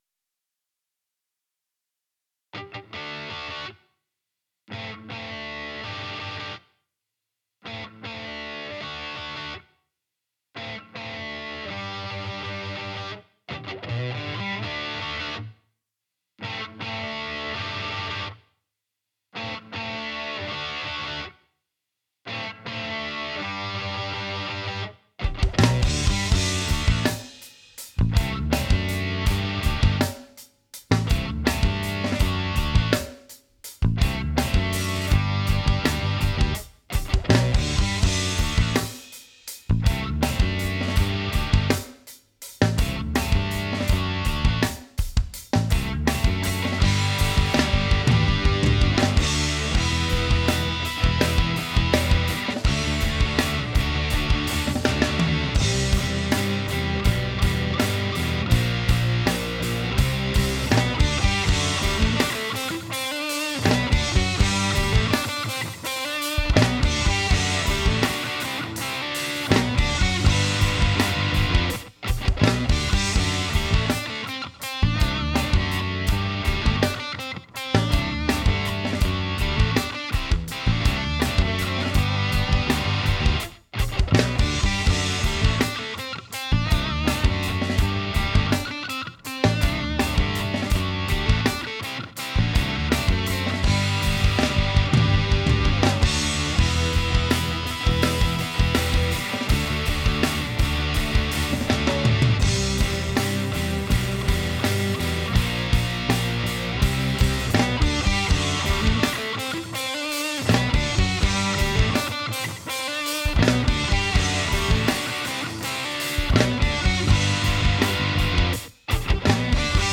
One thing that concerns me is that I might have too much distortion on the rythm guitars, which are by themselves at the very beginning. A couple of the chords seem to maybe warble out too much. Let me know if I'm on the right track with this: Attachments Any Flavor You Like No vox.mp3 Any Flavor You Like No vox.mp3 7.6 MB · Views: 35